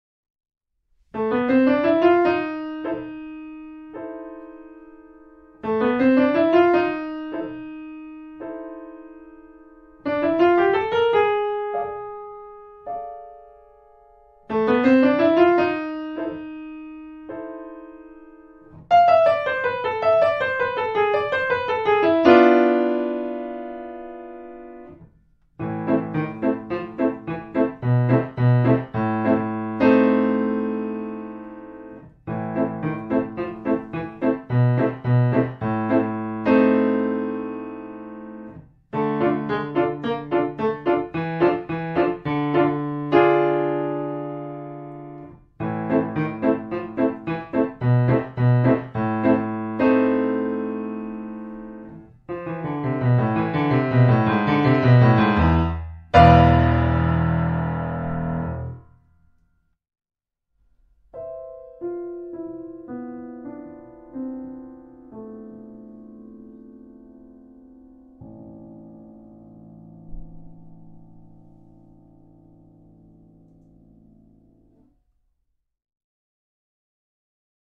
Mystery Fantasy